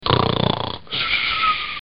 Snore Sound 6